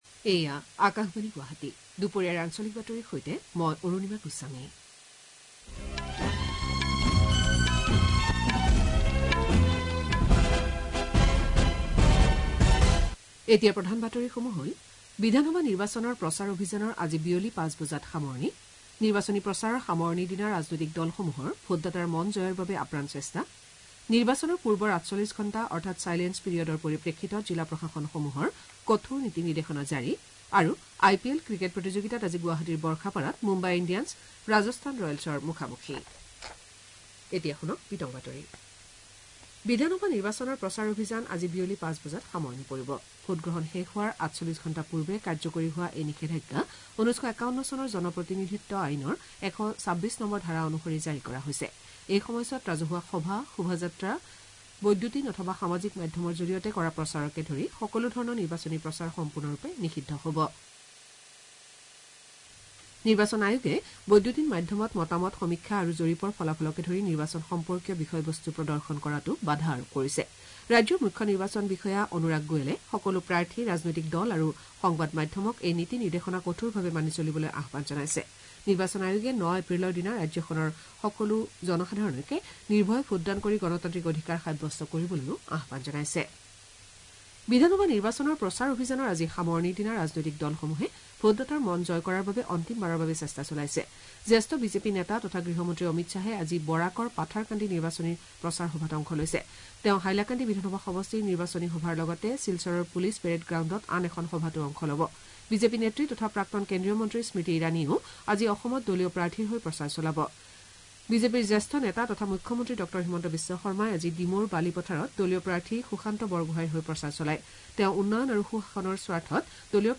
Access Bulletins From Cities
Assamese-News-1205-hrs-0.mp3